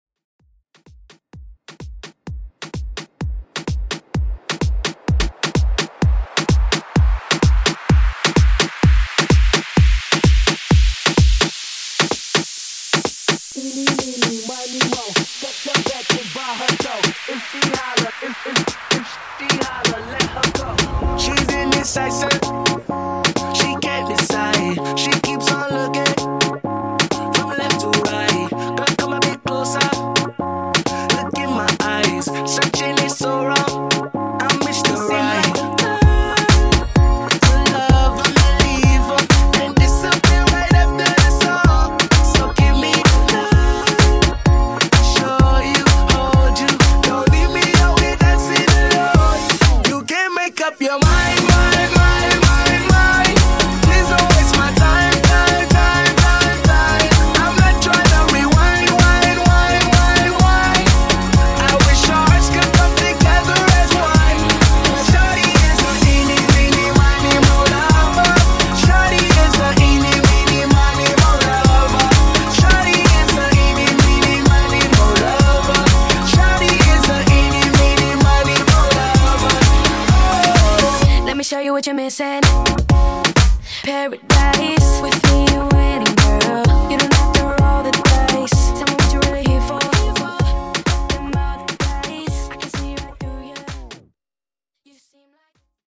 Genres: 2000's , RE-DRUM , ROCK
Clean BPM: 155 Time